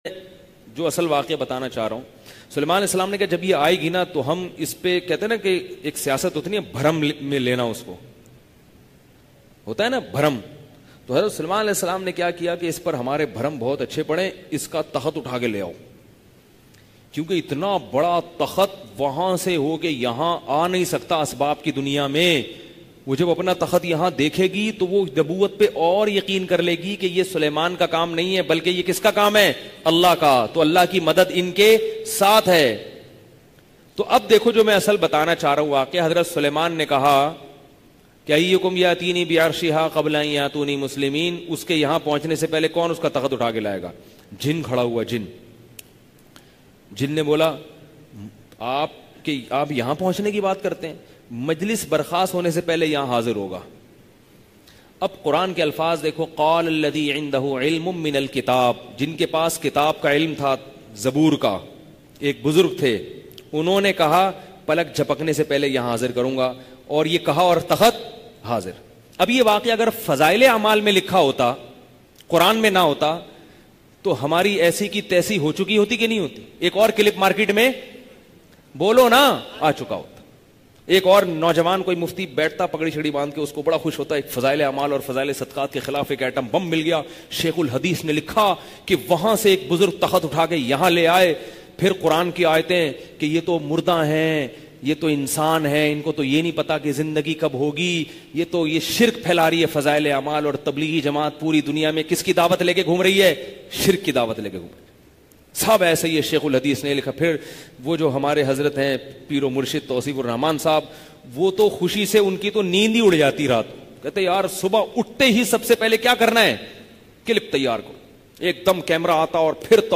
Fazail e amaal ki haqeeqat bayan mp3